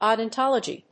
音節o・don・tol・o・gy 発音記号・読み方
/òʊdɑntάlədʒi(米国英語)/